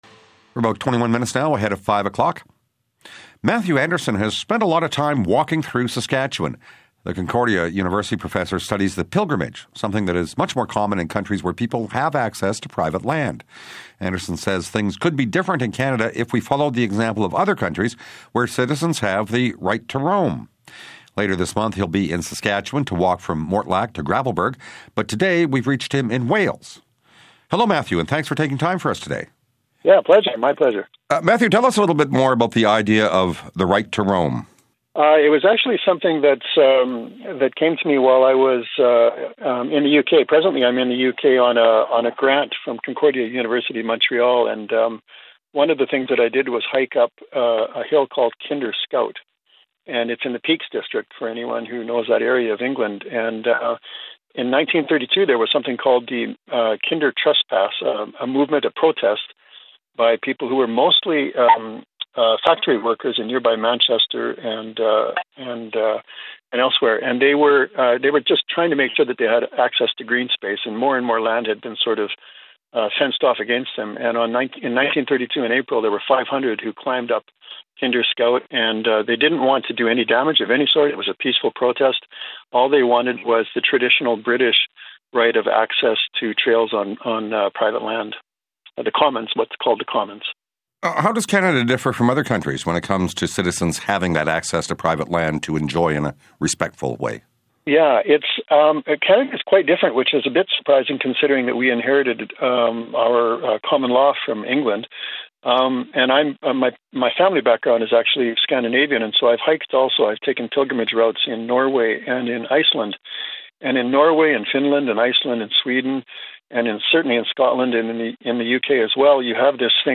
CBC SK interview about the Right to Roam in Canada
right-to-roam-interview-cbc-afternoon-edition-sask.mp3